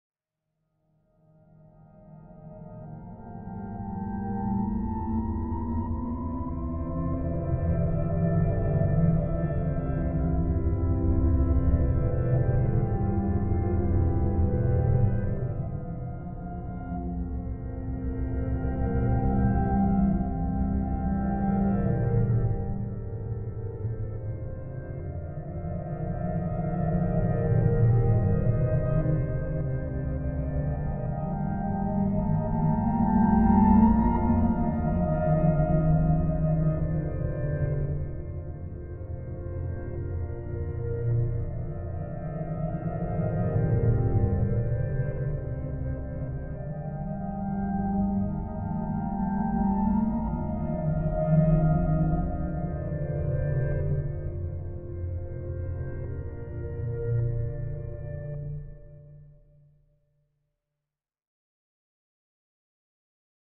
Music: Eerie Piano, With Reverb. Reversed.